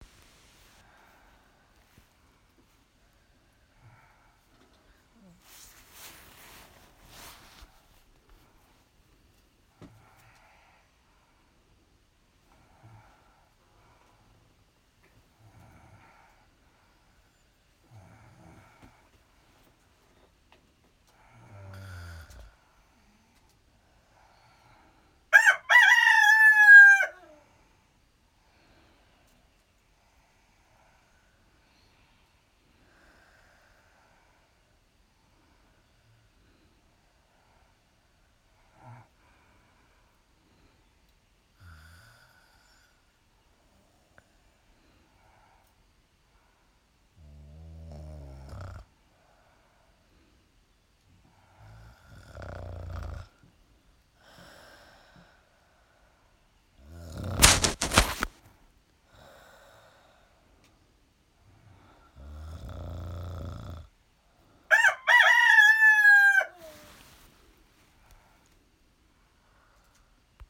A rooster at Cancartu